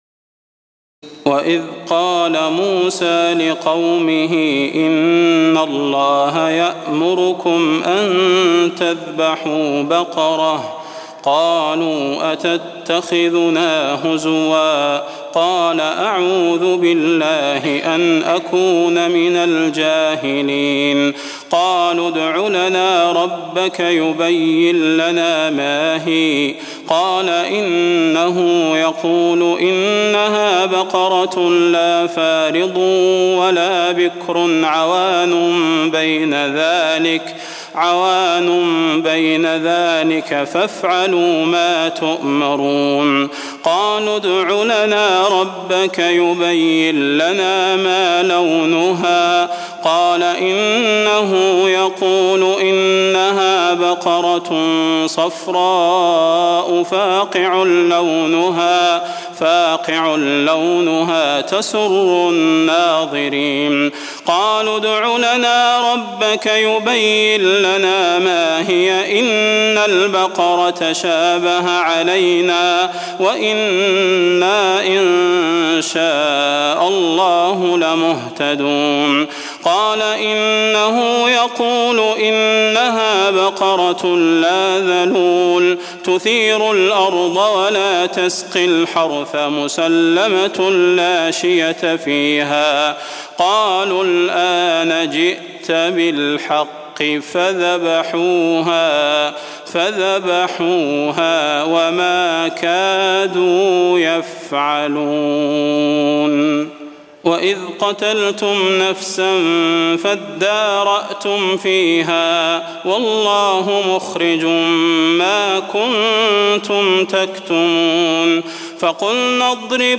تراويح الليلة الأولى رمضان 1423هـ من سورة البقرة (67-105) Taraweeh 1st night Ramadan 1423H from Surah Al-Baqara > تراويح الحرم النبوي عام 1423 🕌 > التراويح - تلاوات الحرمين